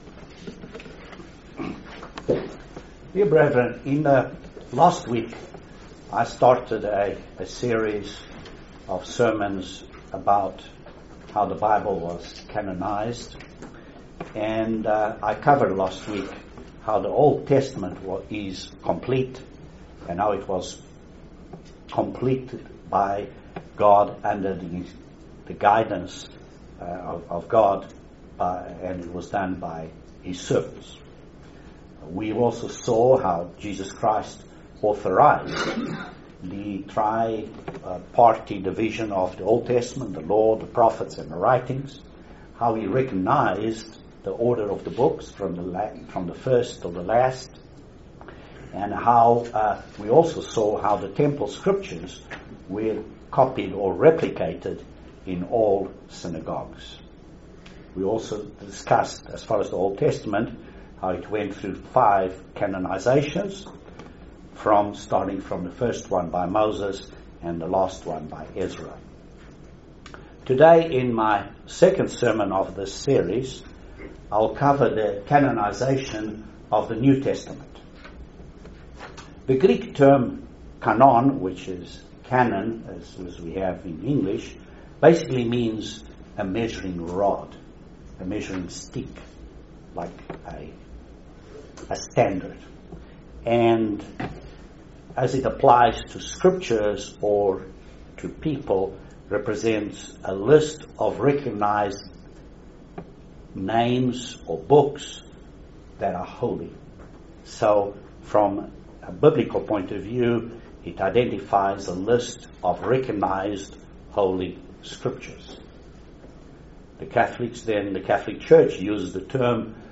Very interesting Sermon on How the books of the New Testament were canonized. Join us for this amazing study of how the books of the New Testament were canonized.